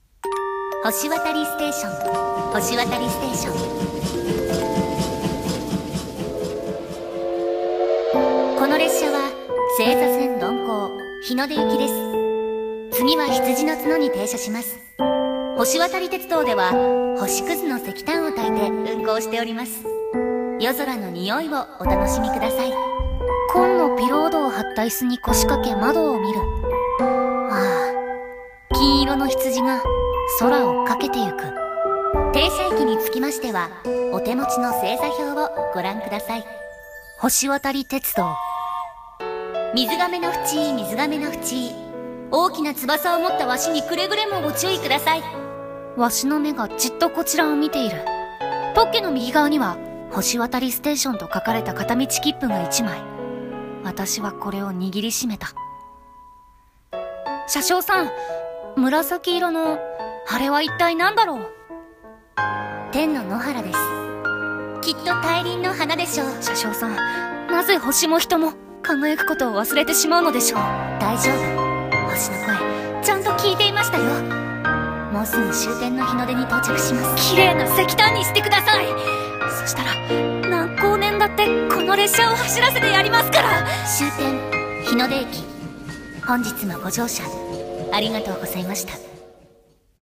CM風声劇｢星渡り鉄道｣お手本